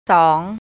2 sawng สอง